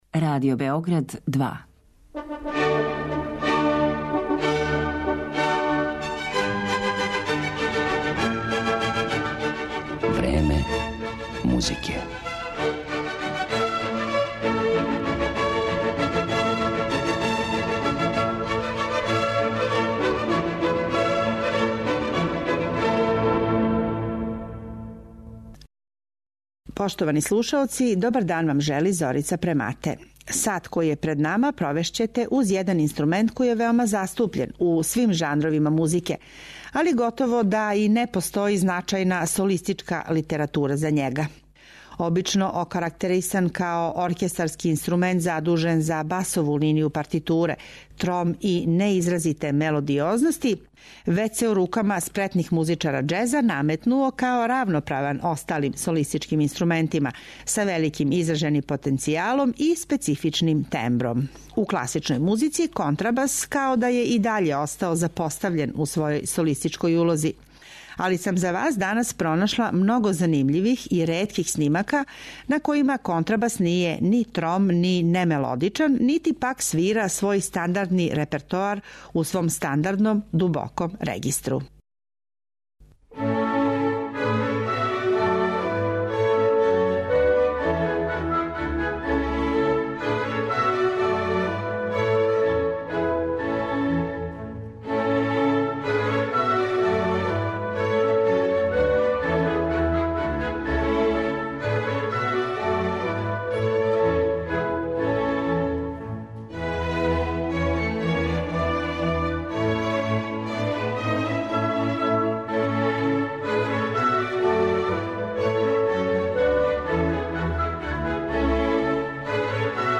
Да то не мора бити тако доказују, између осталих, виртуоз на овом инструменту, Гари Кар, композитор Стефано Скоданибио и необичан ансамбл састављен искључиво од контрабаса, Bassiona amorosa.